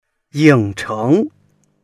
ying3cheng2.mp3